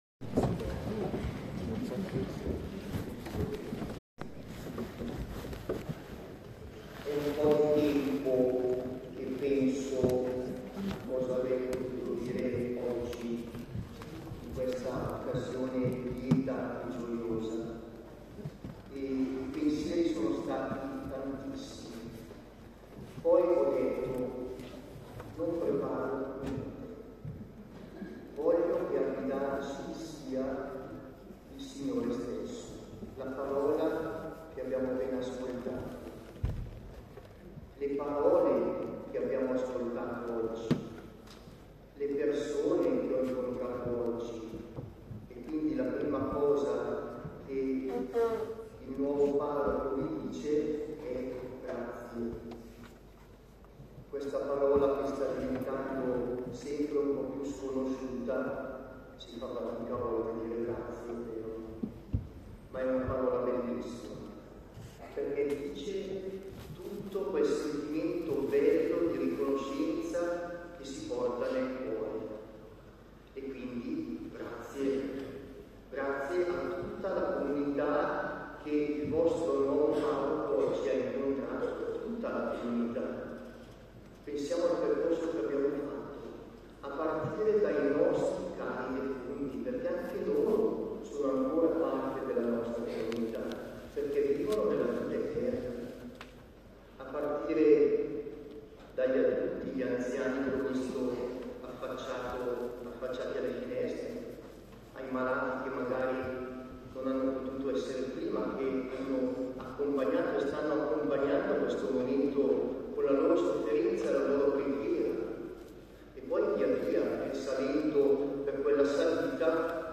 L’audio dell’omelia: